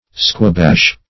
Search Result for " squabash" : The Collaborative International Dictionary of English v.0.48: Squabash \Squa*bash"\ (skw[.a]*b[a^]sh"), v. t. To crush; to quash; to squash.